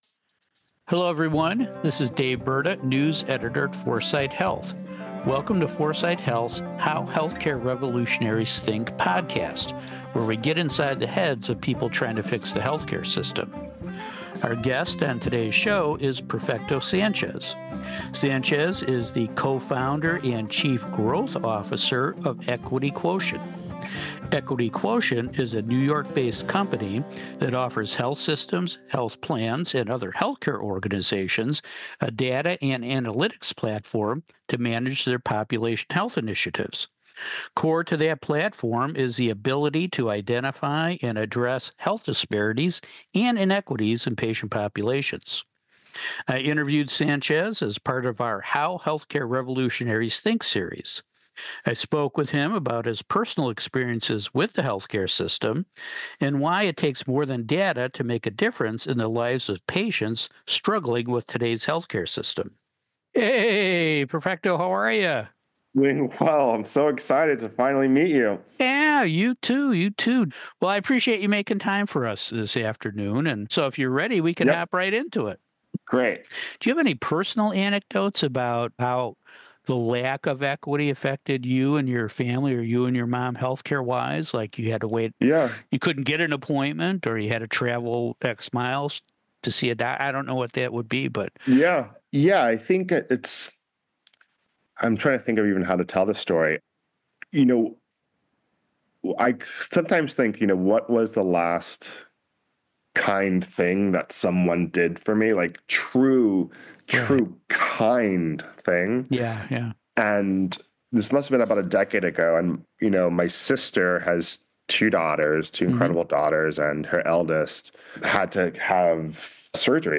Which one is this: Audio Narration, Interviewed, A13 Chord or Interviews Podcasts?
Interviews Podcasts